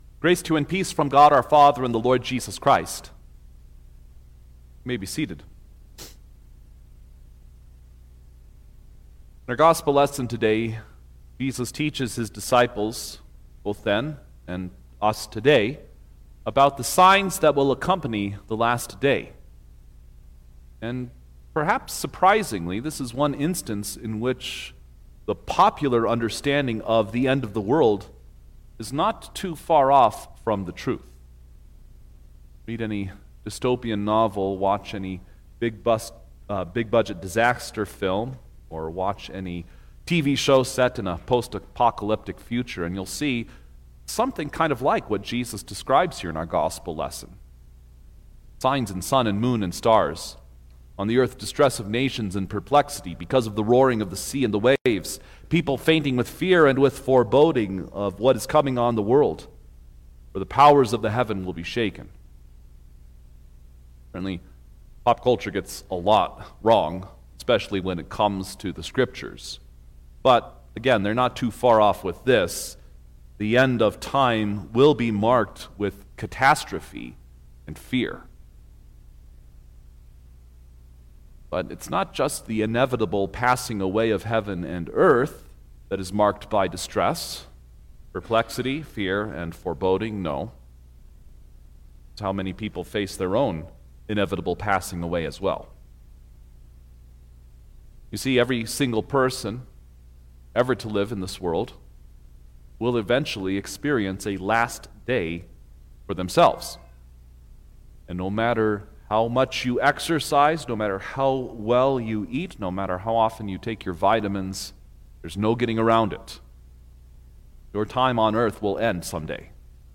December-7_2025_Second-Sunday-in-Advent_Sermon-Stereo.mp3